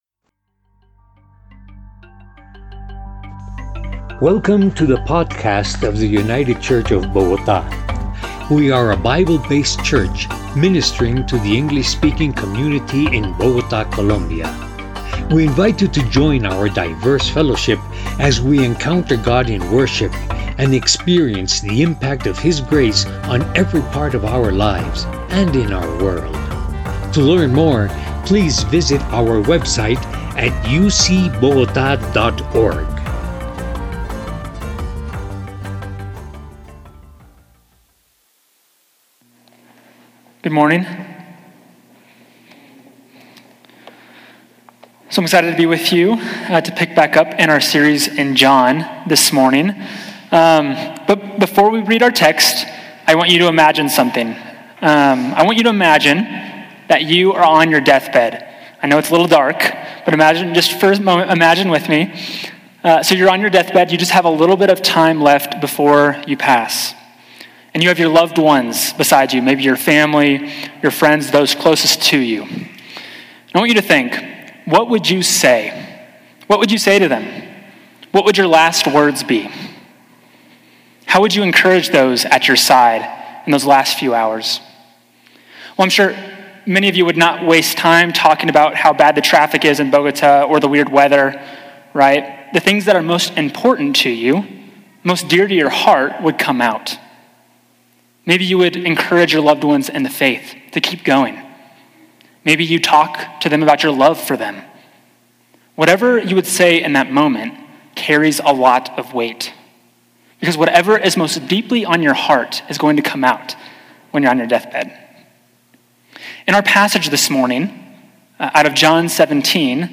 Our Greatest Witness – United Church of Bogotá